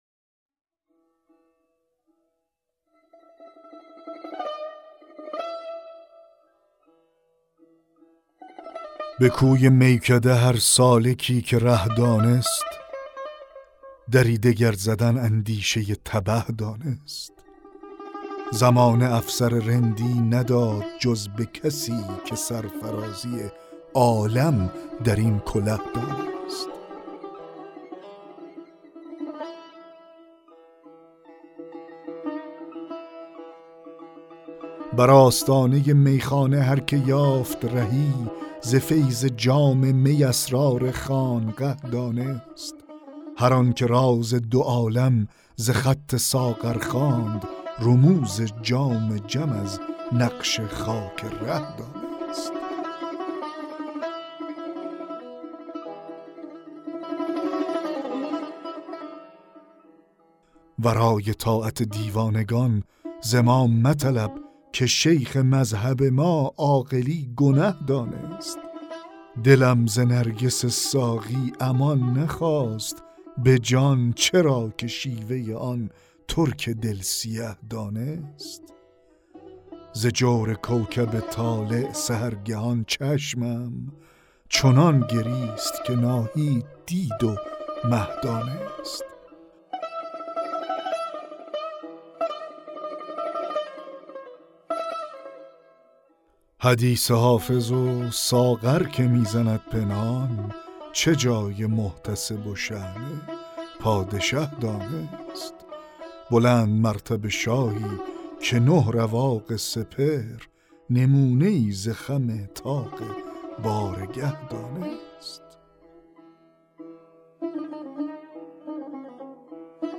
دکلمه غزل 47 حافظ
دکلمه-غزل-47-حافظ-به-کوی-میکده-هر-سالکی-که-ره-دانست.mp3